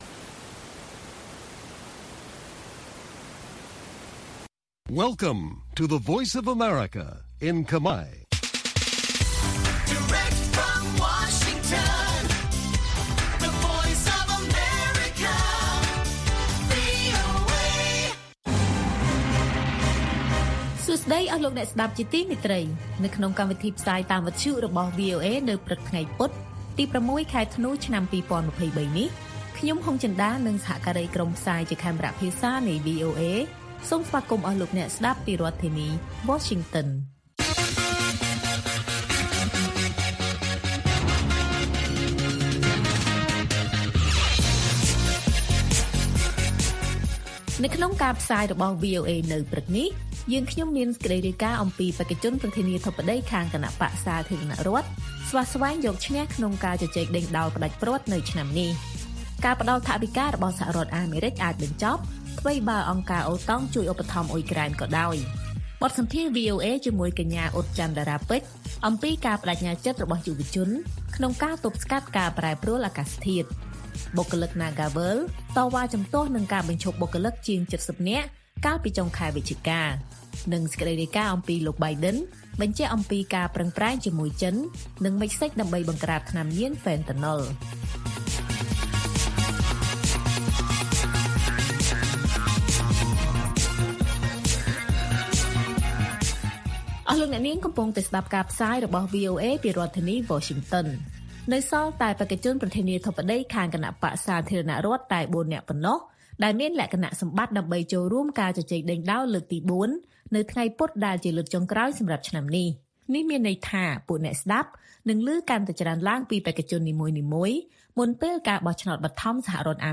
ព័ត៌មាននៅថ្ងៃនេះមានដូចជា បេក្ខជនប្រធានាធិបតីខាងគណបក្សសាធារណរដ្ឋស្វះស្វែងយកឈ្នះក្នុងការជជែកដេញដោលផ្តាច់ព្រ័ត្រនៅឆ្នាំនេះ។ បទសម្ភាសន៍វីអូអេ